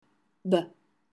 Listen to the pronunciation of the consonants in the chart above by clicking the plus icon by each and use the recorder tool below to record your pronunciation.
Consonant b